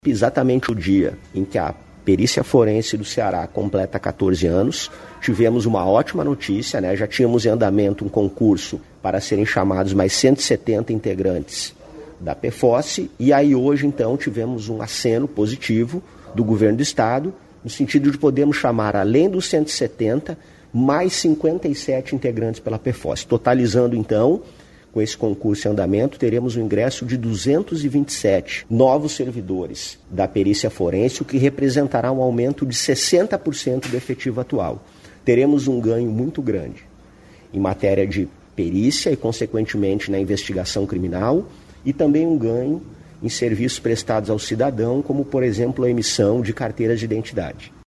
O secretário da Segurança pública, Sandro Caron, destacou que o incremento vai resultar em melhores serviços para os cearenses.